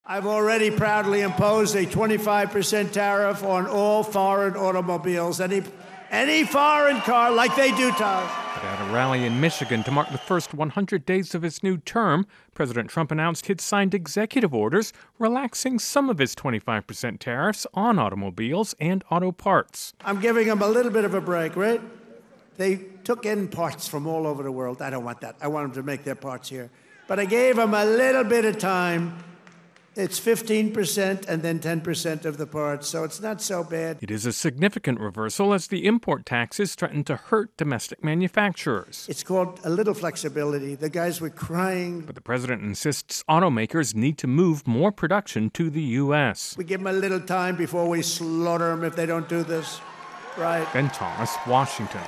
((opens with actuality))